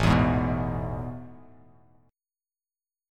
AM7sus2 Chord
Listen to AM7sus2 strummed